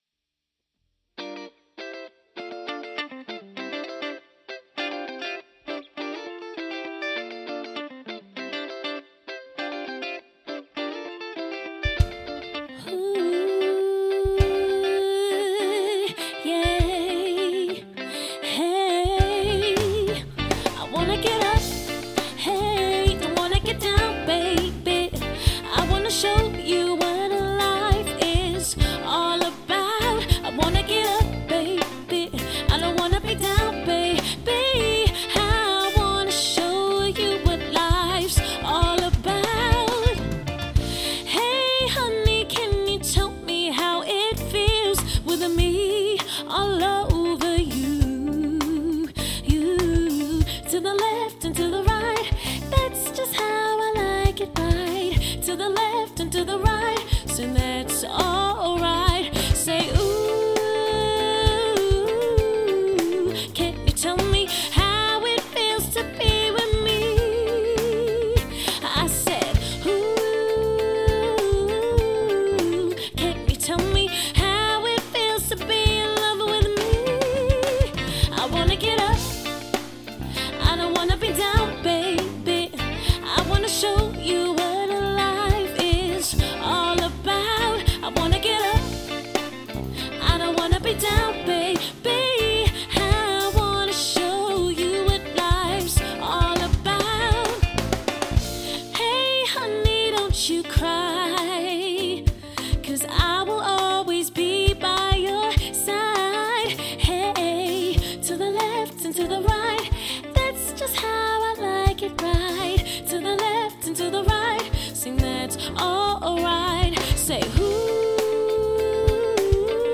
soulful pop and R&B influenced songs
As the band developed we started to record demos of the songs we had been writing together in my flat in Kilburn.